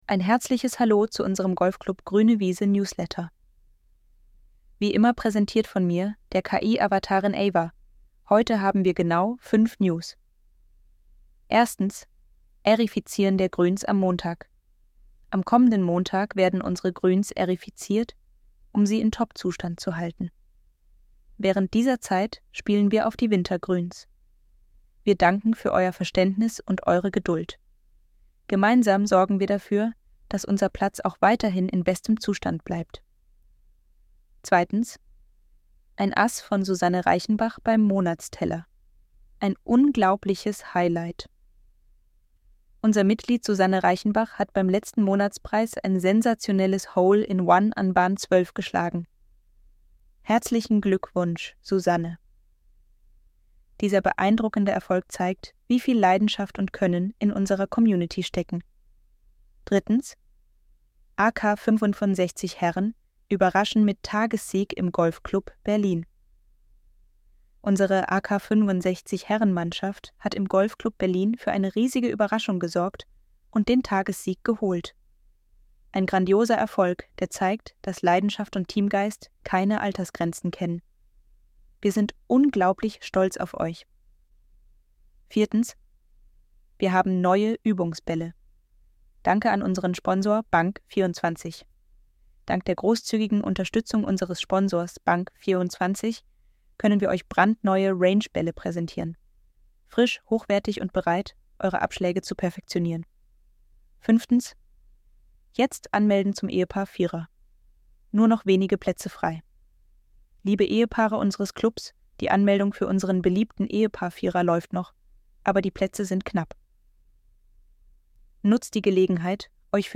Lösung durch KI-Audio „Text-to-Speech"
Die KI analysiert den Text, erkennt Betonungen und Intonationen und erstellt eine natürlich klingende Sprachwiedergabe, die professionell und ansprechend ist.
So könnte Ihr zukünftiger Voice-Newsletter klingen
Um Ihnen einen Eindruck zu vermitteln, wie sich das Ergebnis anhören könnte, fügen wir ein Beispiel eines fiktiven Newsletters bei.
Newsletter-01-Golfclub-Gruene-Wiese.mp3